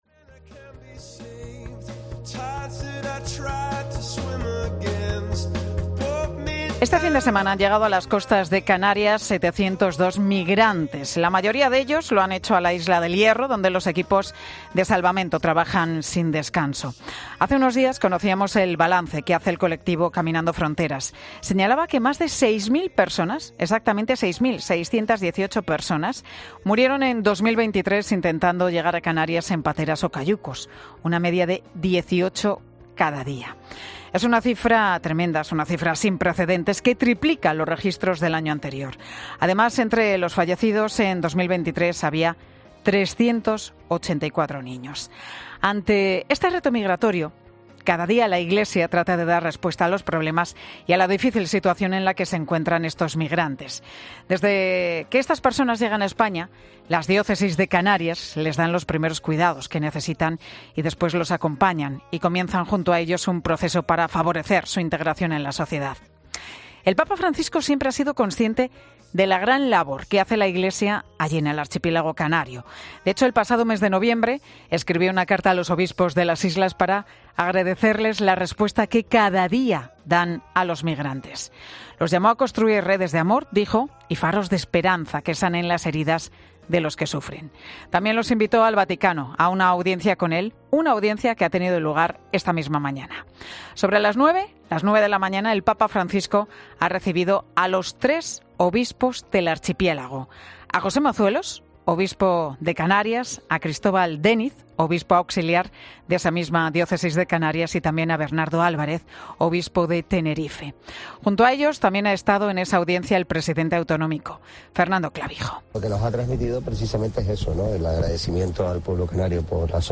Escucha la entrevista al obispo de Canarias, José Mazuelos, en 'Mediodía COPE'